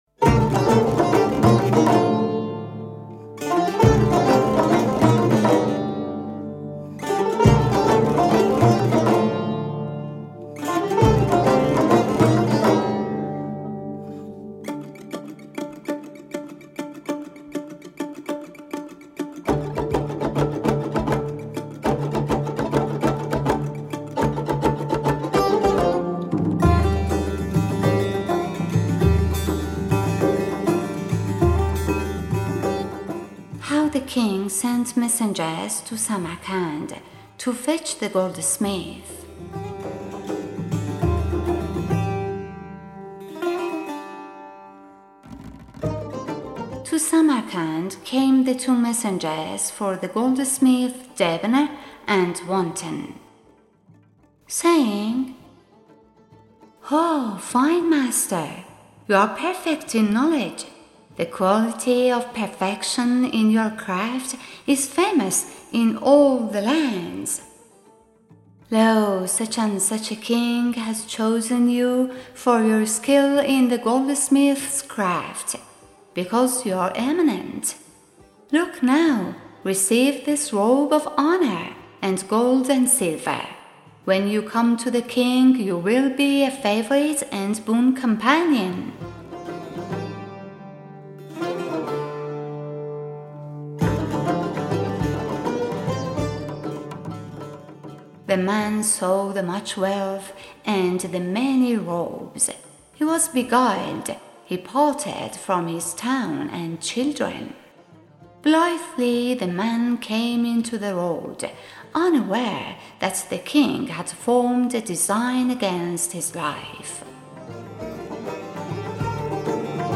Music by: Salar Aghili